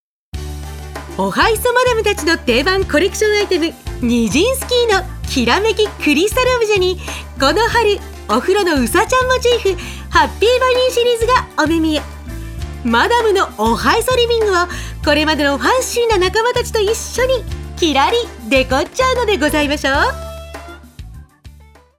アナウンサー
太くて強い声が特徴で、強靭な声帯の持ち主。
ボイスサンプル